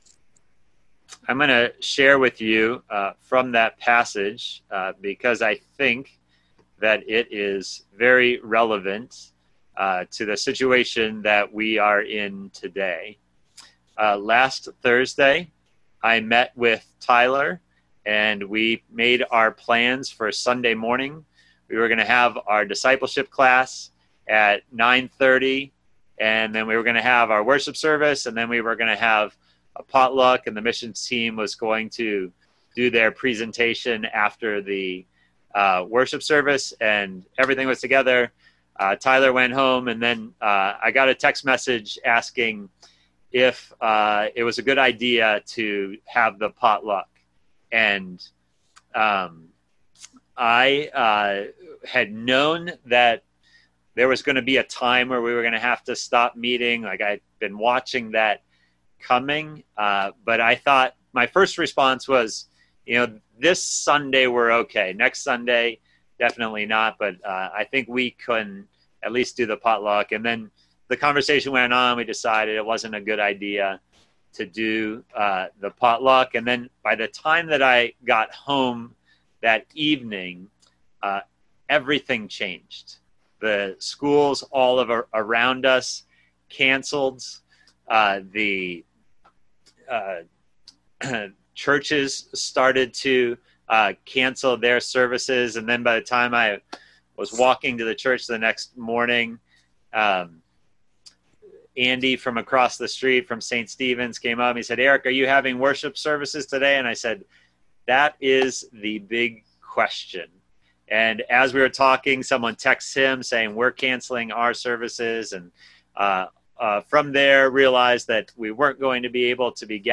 Sermon
via Zoom on March 22, 2020.